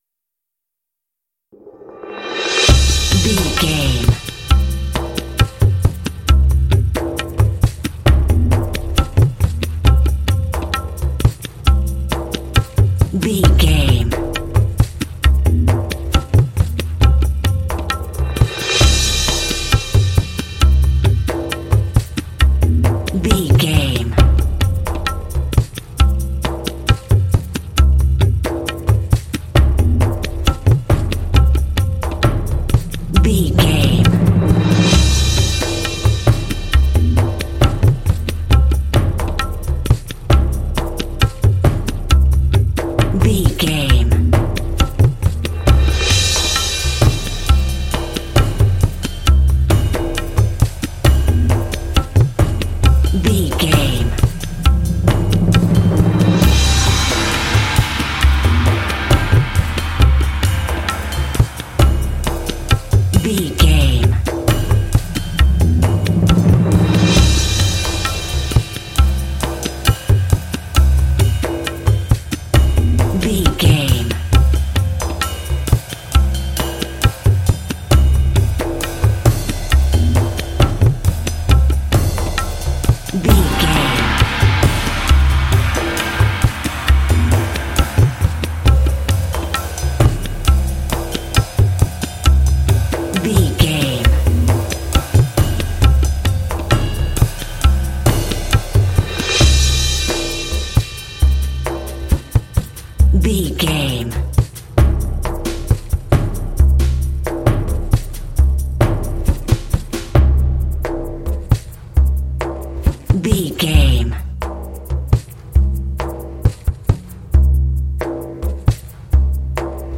Uplifting
Aeolian/Minor
Slow
mystical
dreamy
ethereal
peaceful
cinematic